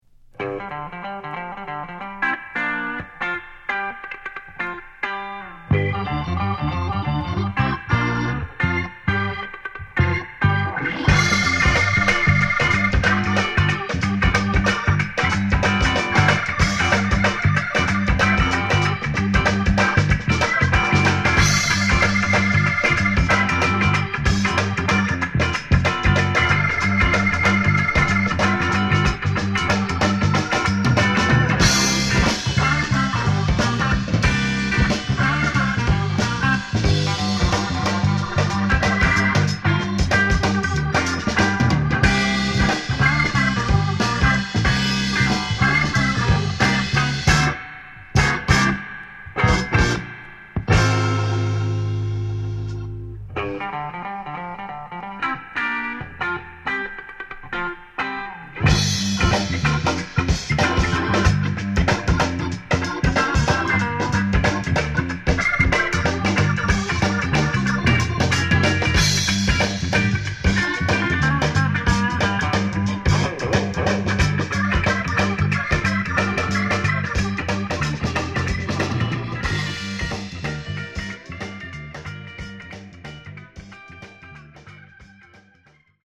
Genre: Soul Instrumentals
Great breaks and extremely funky beats!